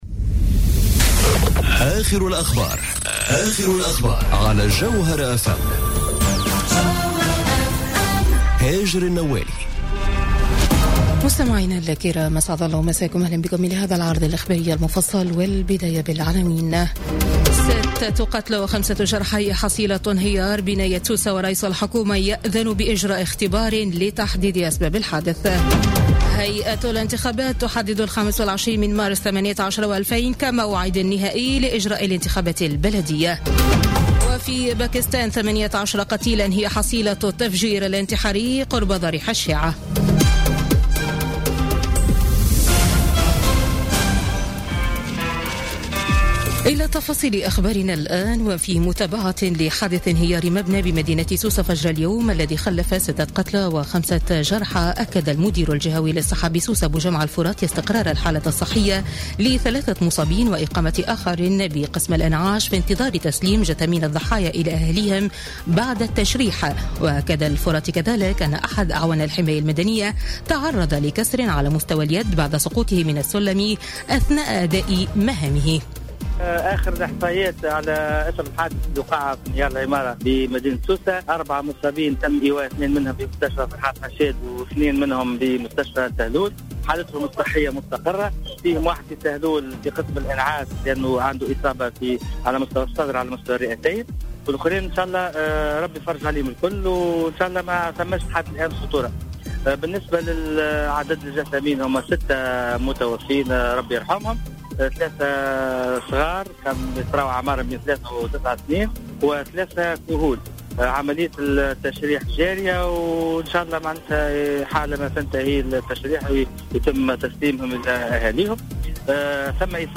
نشرة أخبار السابعة مساء ليوم الخميس 5 أكتوبر 2017